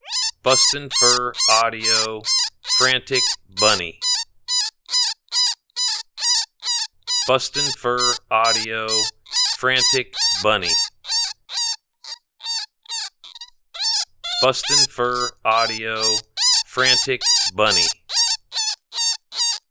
Frantic Bunny is a small rabbit in distress with great cadence for calling in all predators, all year around.